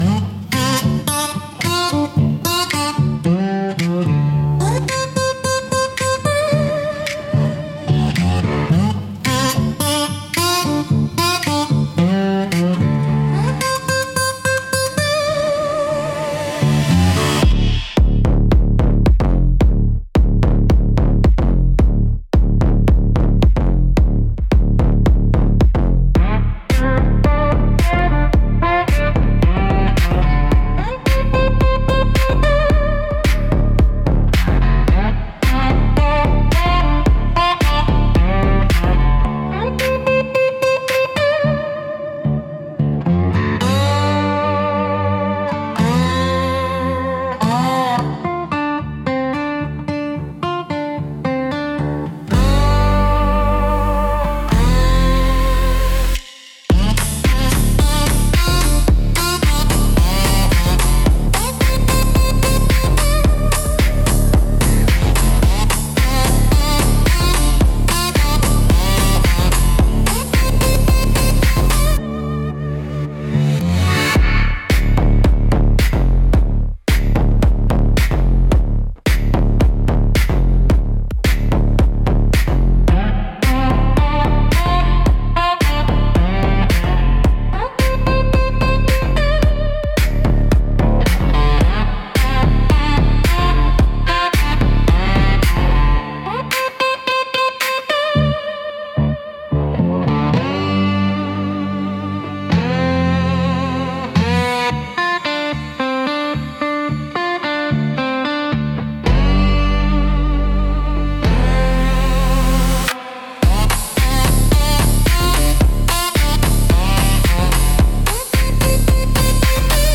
Instrumental - Bayou Bells